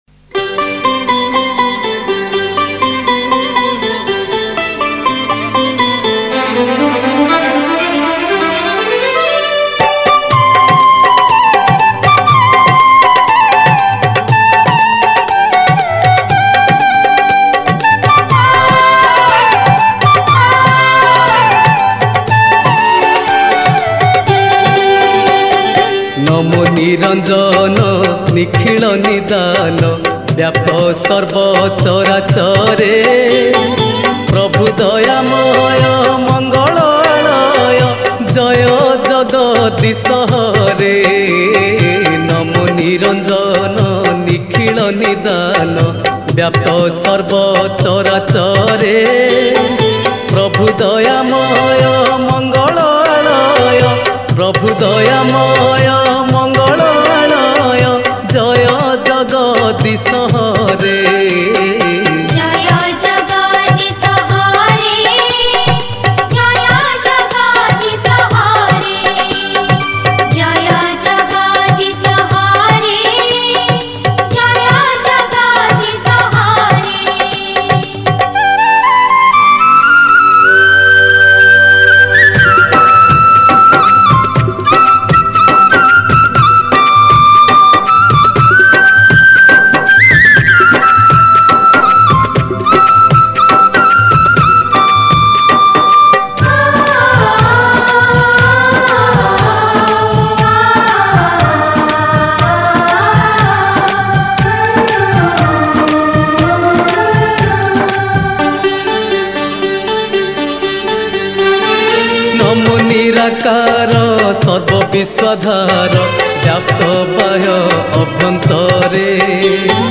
Category: Prathana